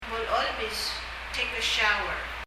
molecholb　　[mɔlɛ ? ɔlb]　　シャワーを浴びる　　take shower
発音